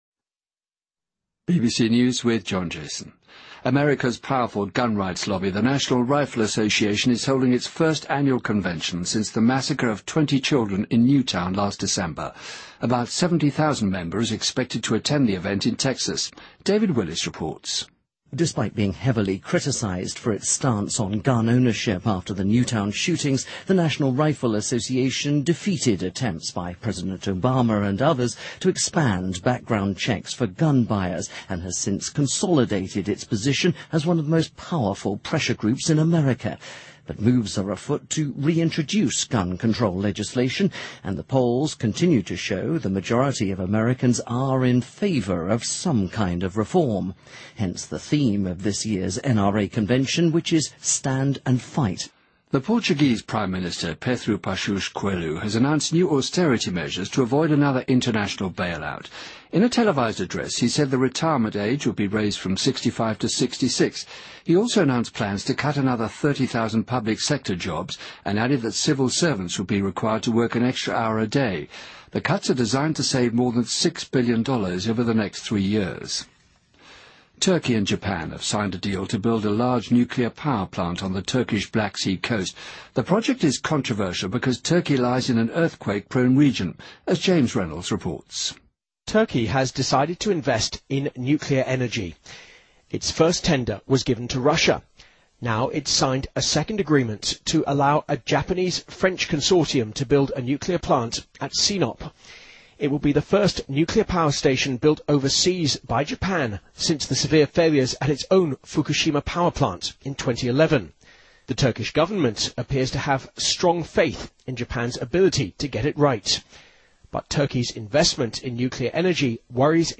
BBC news,2013-05-04